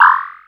ihob/Assets/Extensions/RetroGamesSoundFX/Jump/Jump3.wav at master
Jump3.wav